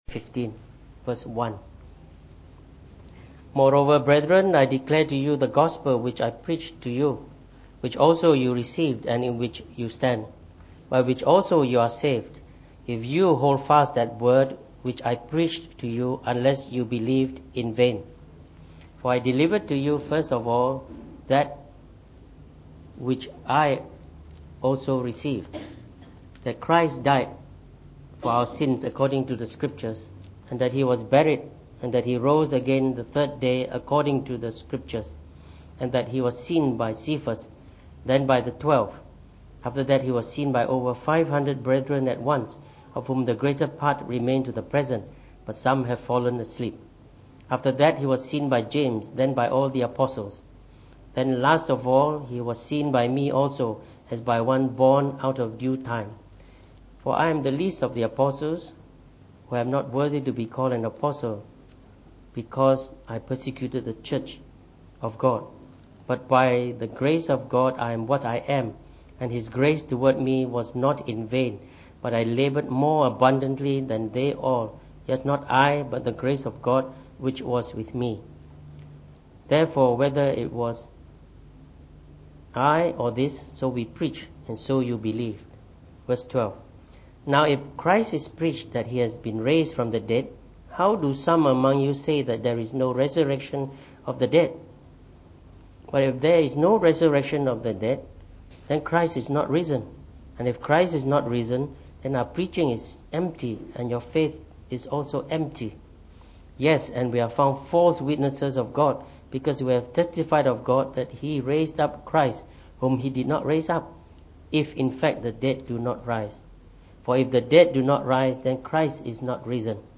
From our morning Easter service from 1 Corinthians.